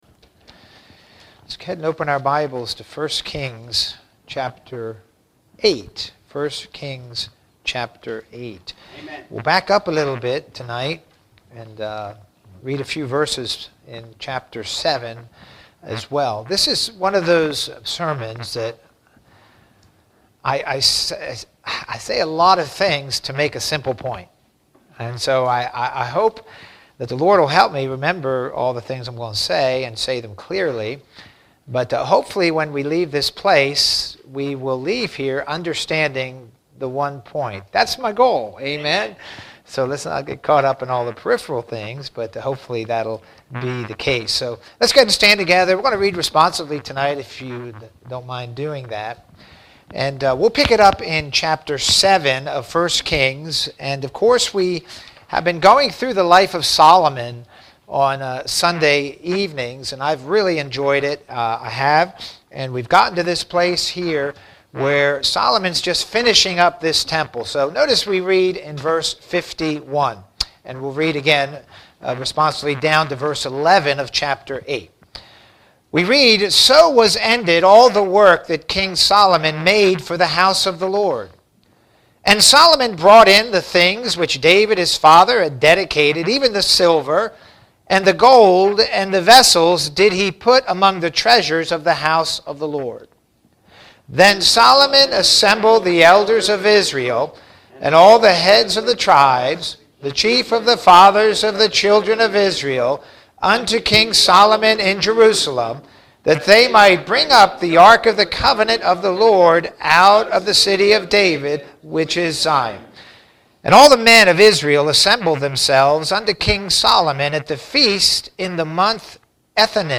The Prioritizing of a King | SermonAudio Broadcaster is Live View the Live Stream Share this sermon Disabled by adblocker Copy URL Copied!